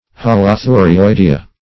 Holothurioidea \Hol`o*thu`ri*oi"de*a\, n. pl. [NL. See